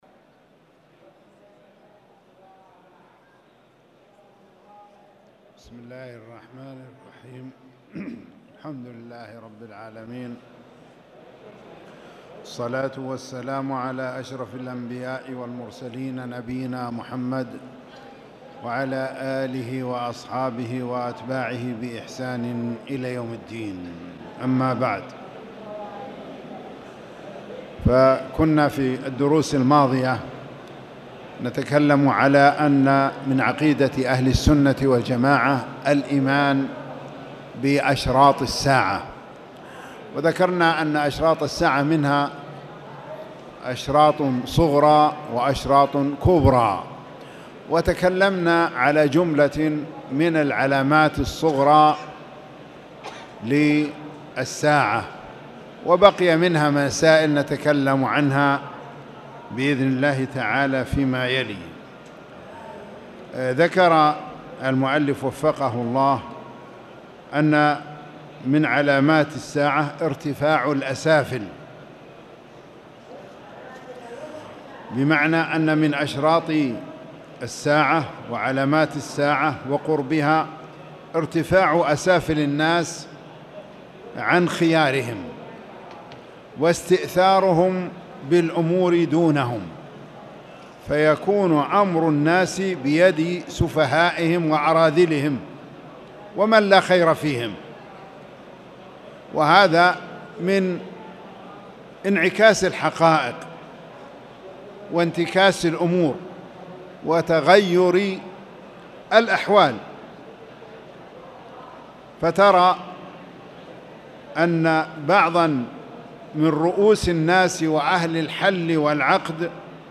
تاريخ النشر ١٦ جمادى الأولى ١٤٣٨ هـ المكان: المسجد الحرام الشيخ